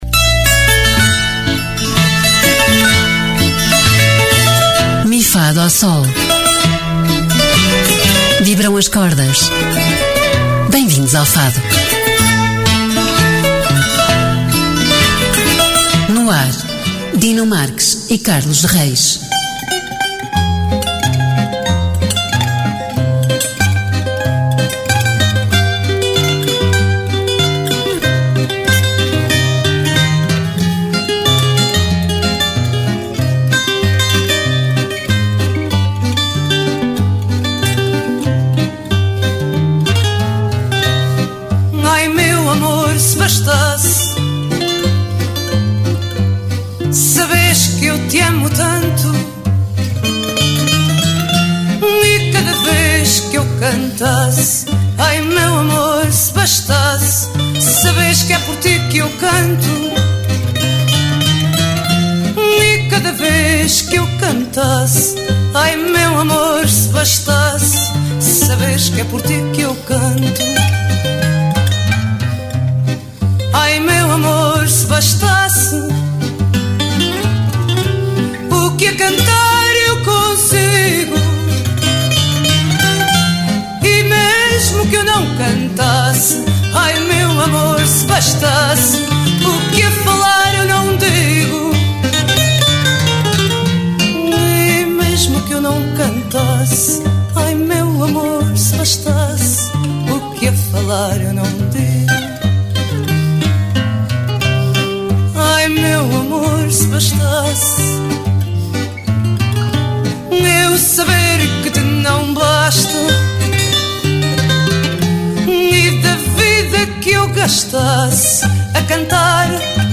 Emissão: 07 de Dezembro 2023 Descrição: Programa inteiramente dedicado ao Fado. Realizado por intérpretes do género musical, Mi-Fá-Dó-Sol foca-se na divulgação de artistas e eventos, sem deixar de lado a história e as curiosidades de tão importante património português!